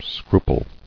[scru·ple]